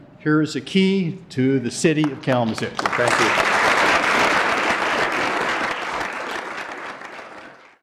KALAMAZOO, MI (WKZO AM/FM) – The NCAA national champion Western Michigan University hockey team appeared at Kalamazoo City Hall last night to hear a resolution honoring their achievements this season.